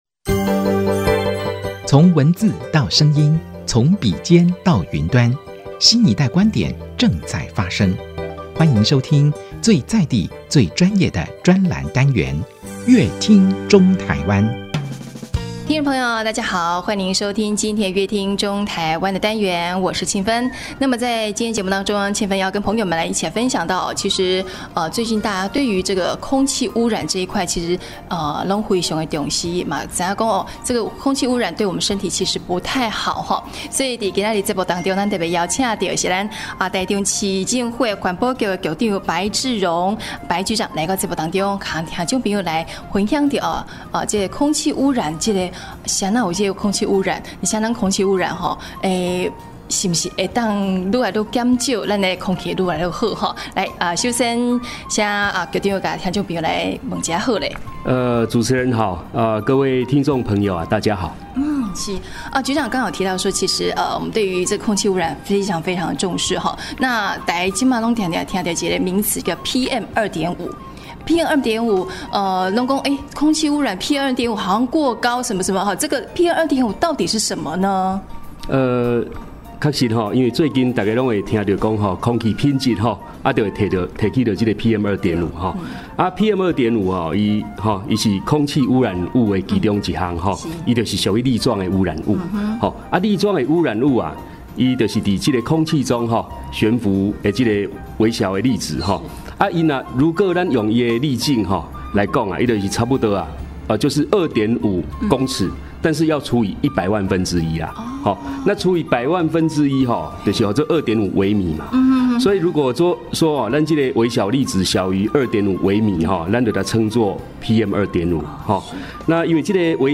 本集來賓：臺中市政府環保局白智榮局長 本集主題：台中市PM2.5濃度持續減量 期許109年符合國家標準 本集內